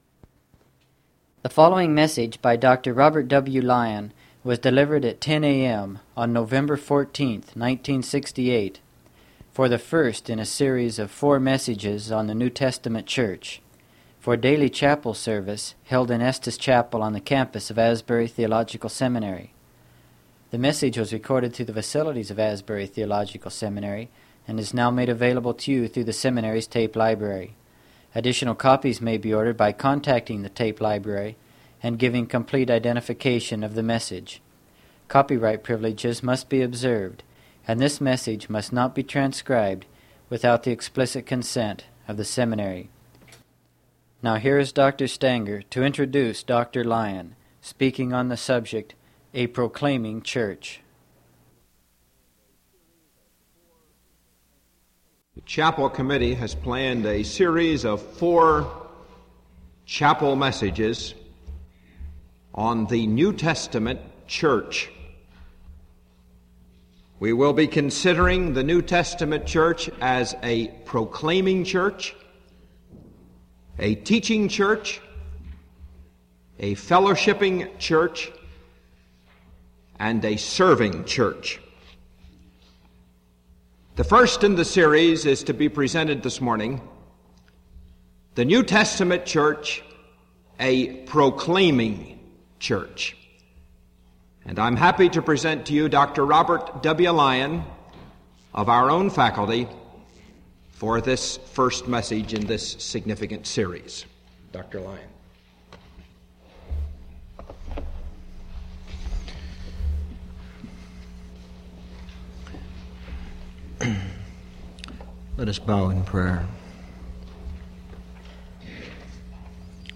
Faculty chapel services, 1968